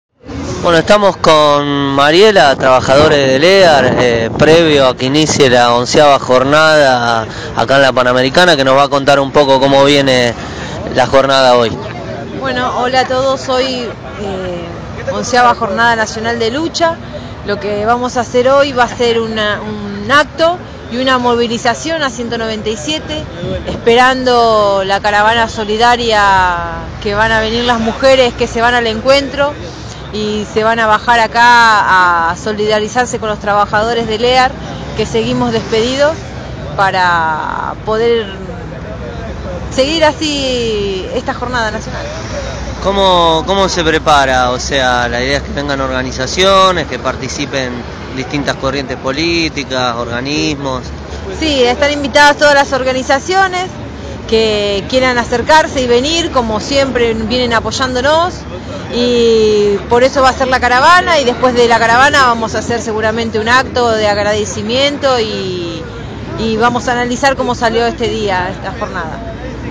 Entrevista a trabajadora despedida de Lear sobre la XI Jornada Nacional de Lucha